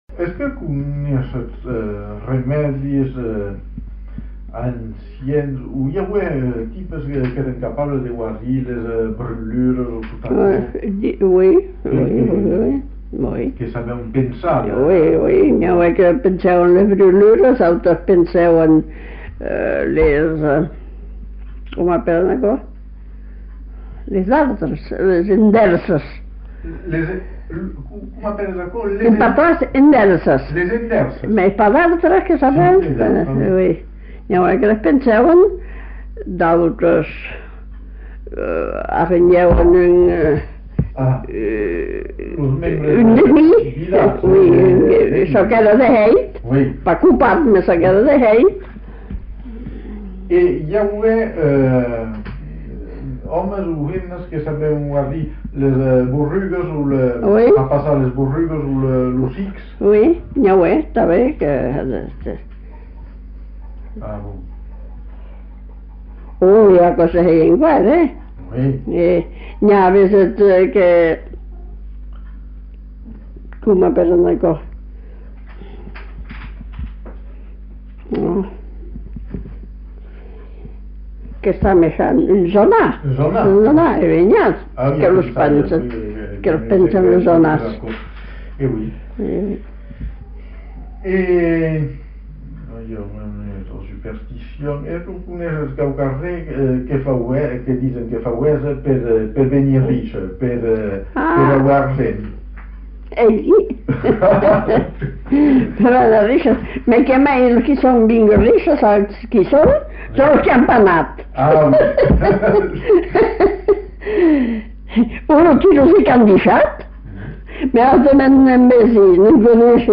Lieu : Sauméjan
Genre : témoignage thématique